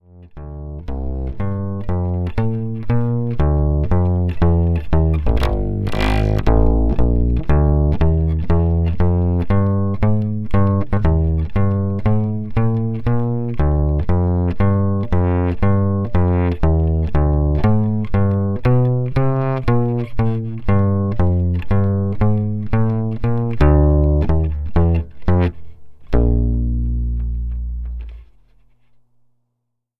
特に気になっていた4弦の音は大幅に改善しました。
これに今回製作した光ピックアップの音ををミックスすると「
低音が豊かになった感じです。